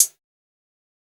drum-slidertick.wav